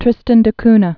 (trĭstən də knə)